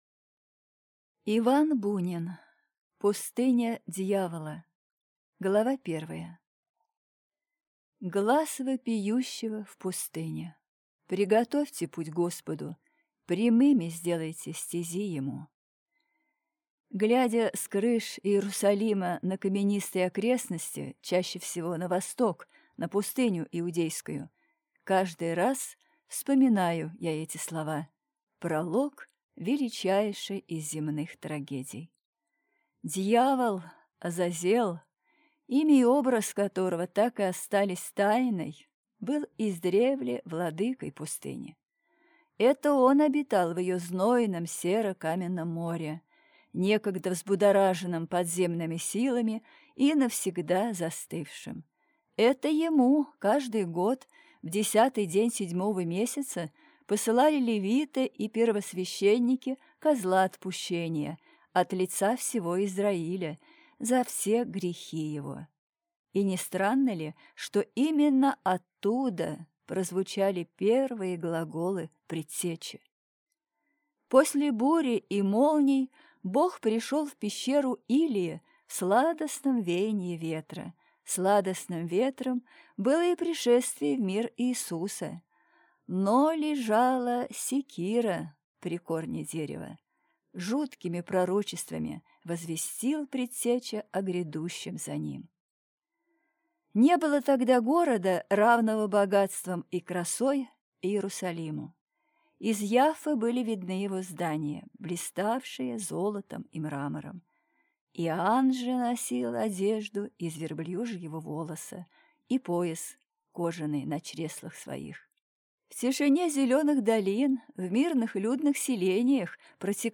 Aудиокнига Пустыня дьявола